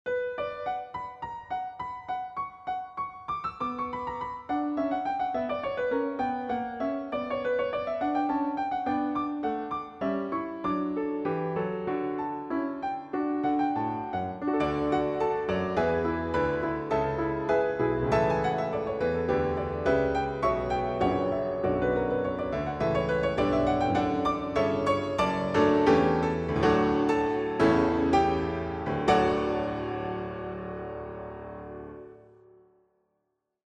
Starałem się sprawdzić możliwości odtwórcze programu Finale 2009 i muszę powiedzieć, że jestem pod wrażeniem.